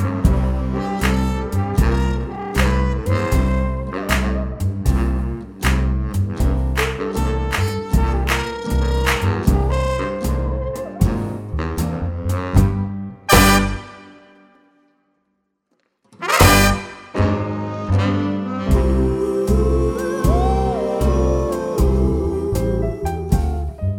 no Backing Vocals Crooners 3:25 Buy £1.50